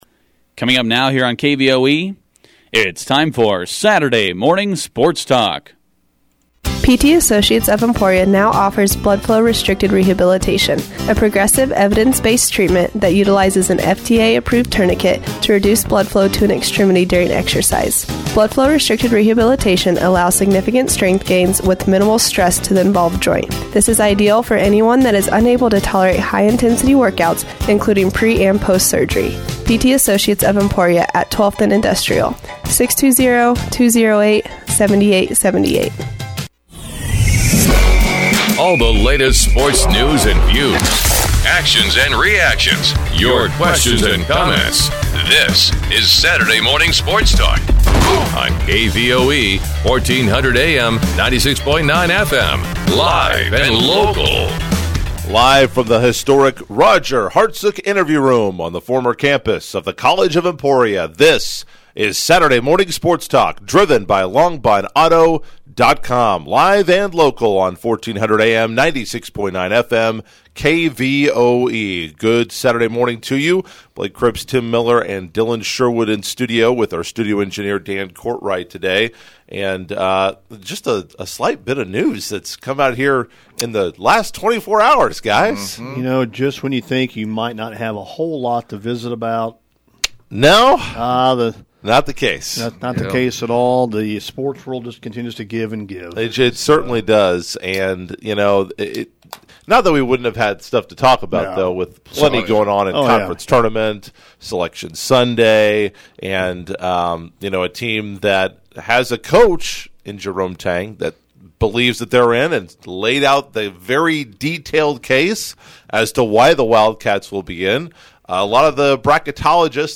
Saturday Morning Sports Talk: Guests including Emporia High basketball coaches